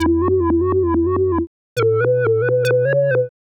Index of /musicradar/uk-garage-samples/136bpm Lines n Loops/Synths